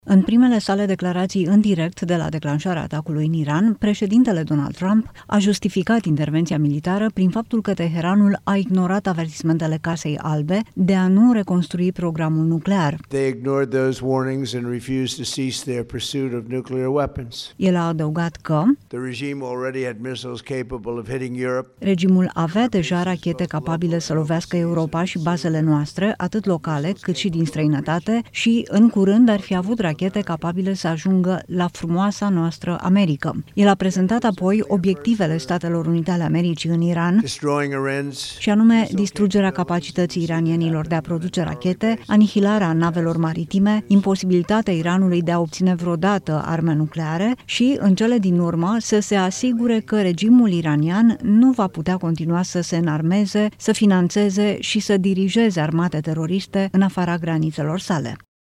În primele sale declarații în direct de la declanșarea atacului în Iran, președintele Donald Trump a justificat intervenția militară prin faptul că Teheranul a ignorat avertismentele Casei Albe de a nu reconstrui programul nuclear.